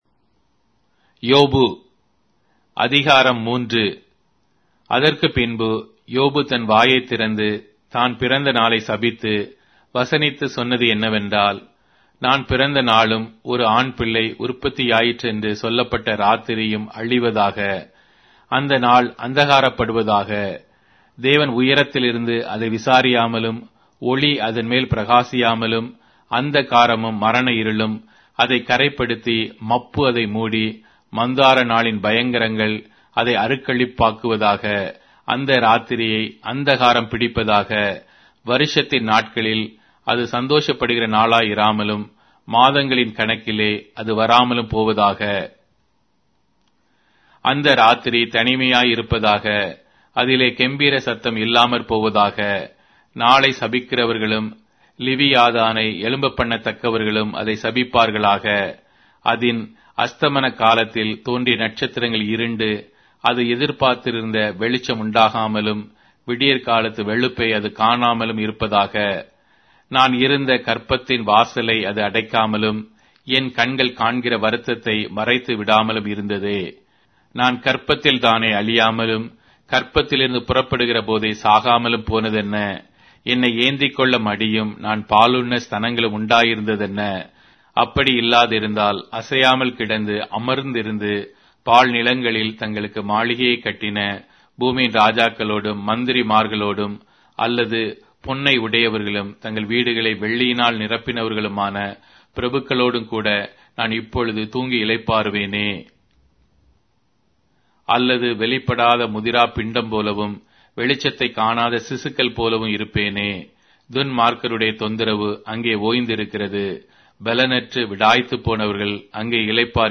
Tamil Audio Bible - Job 23 in Ervkn bible version